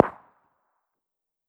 ACE3 / extras / assets / CookoffSounds / shotbullet / far_3.wav